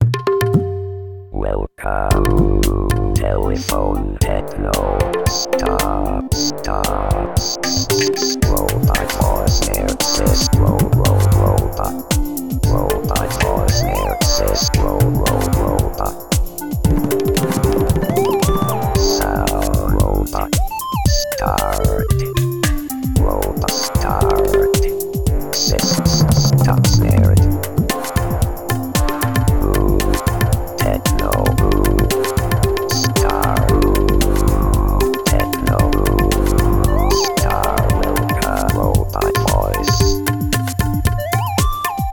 Tecno robot (bucle)
melodía
repetitivo
ritmo
robot
sintetizador